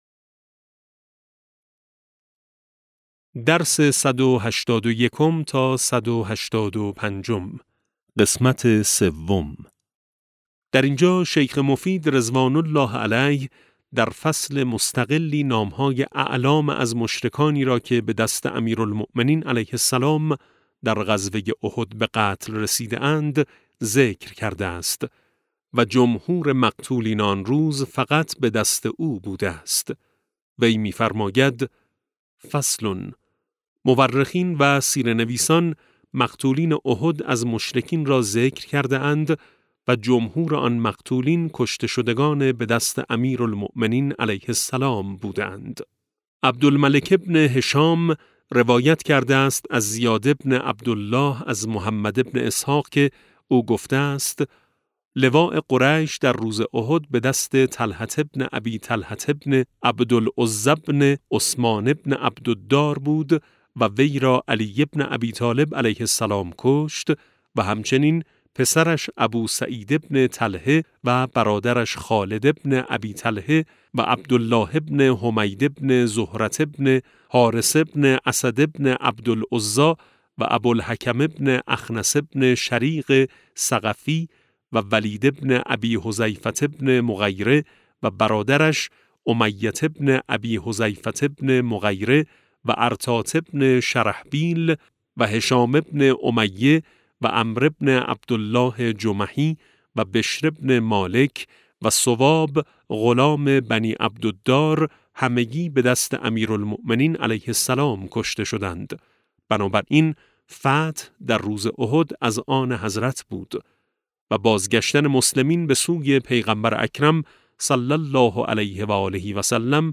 کتاب صوتی امام شناسی ج 13 - جلسه3